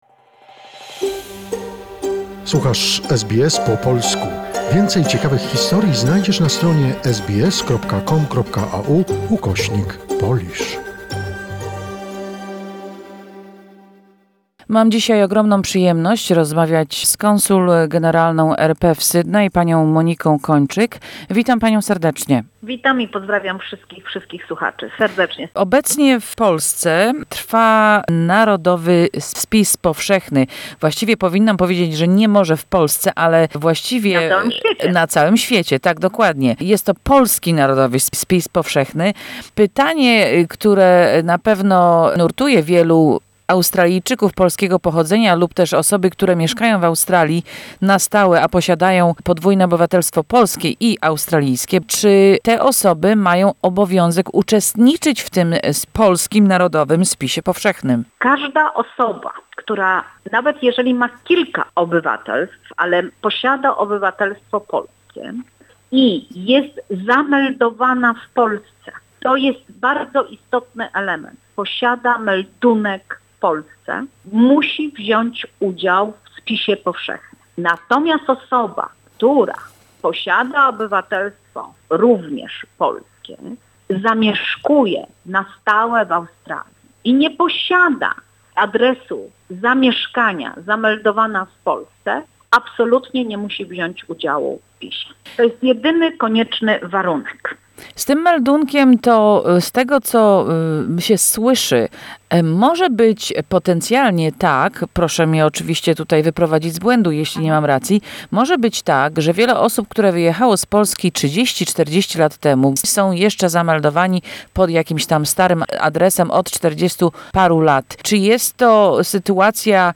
The Polish census is compulsory for all Polish citizens who have a current address registration in Poland, no matter where they live, says Consul General of the Republic of Poland in Sydney, Dr. Monika Kończyk.